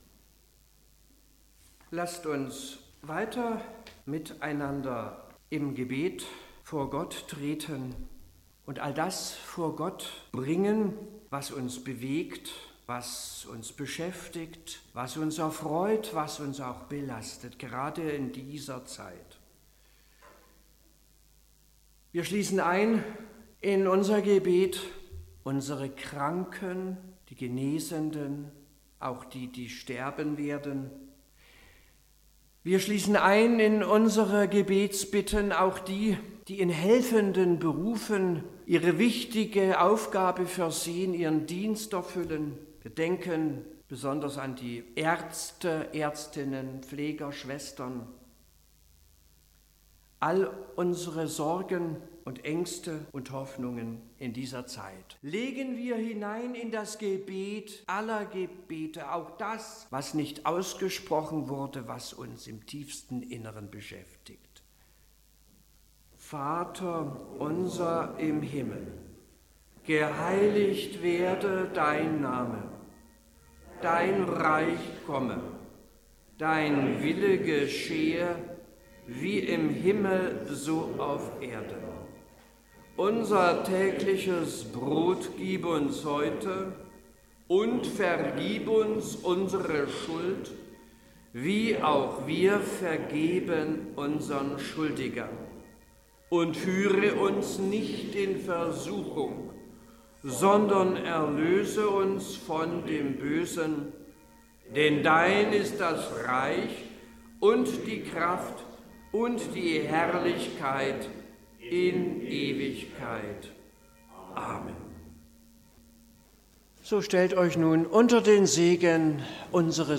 Gottesdienst vom Sonntag Rogate nachhören
Gebet & Segen